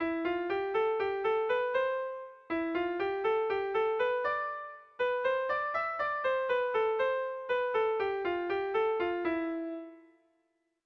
Kontakizunezkoa
Kopla handiaren moldekoa
AAB